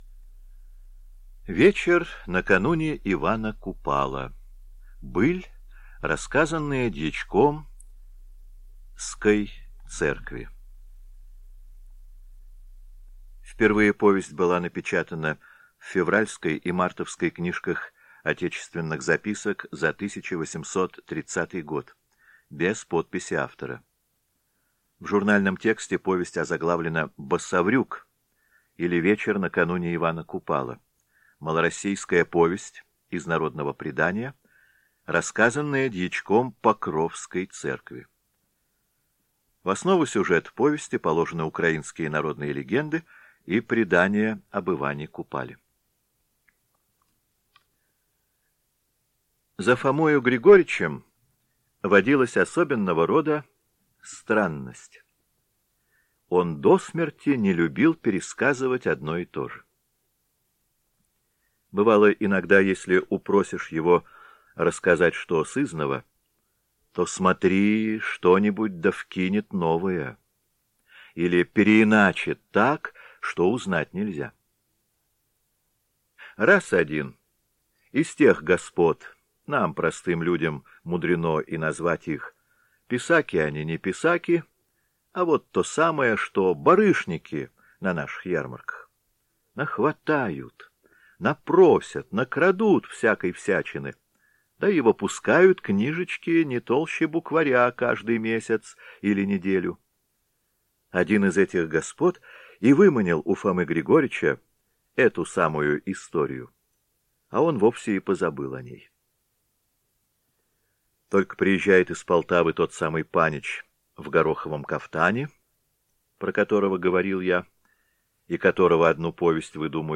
Вечер накануне Ивана Купала - аудио повесть Николая Гоголя - слушать онлайн